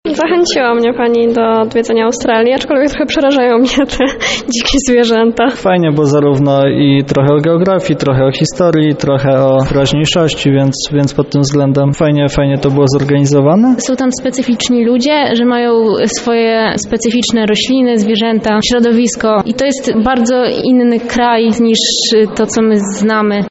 O swoich wrażeniach mówią uczestnicy spotkania: